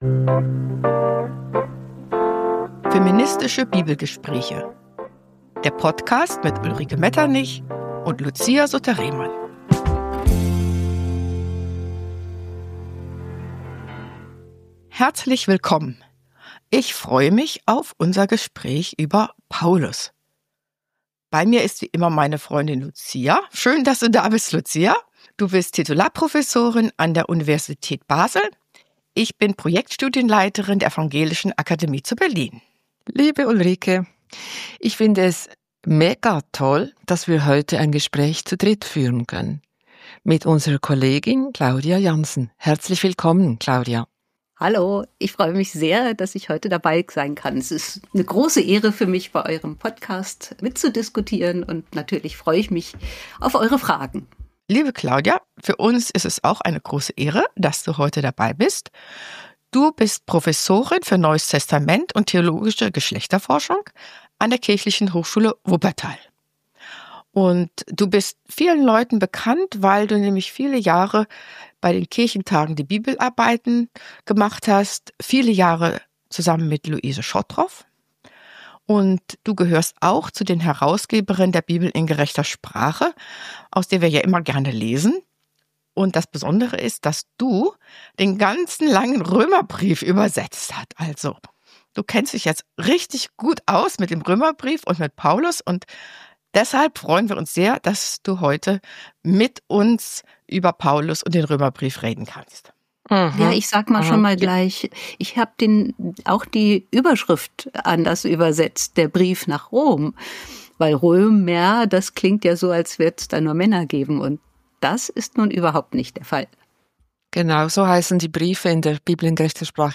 Der erweist sich im Gespräch als Zeugnis einer lebendigen Diskussions- und Glaubensgemeinschaft, in der Frauen und SklavInnen wichtige Aufgaben übernehmen.